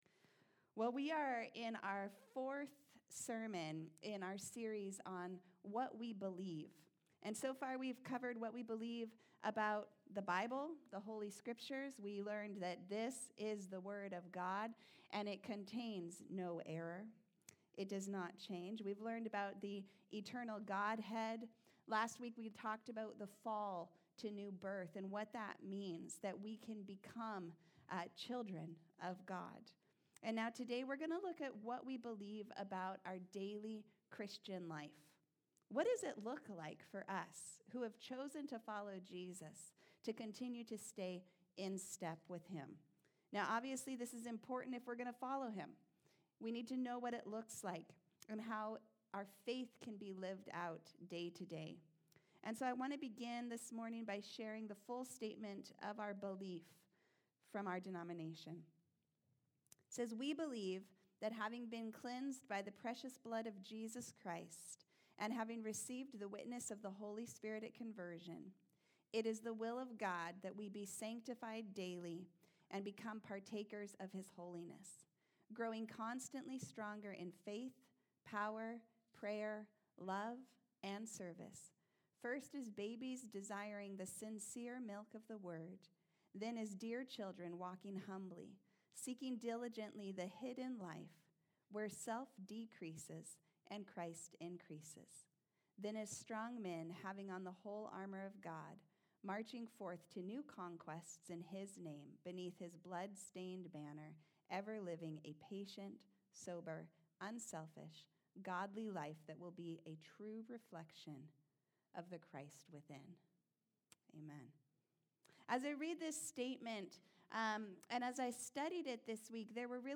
Sermons | Harvest Church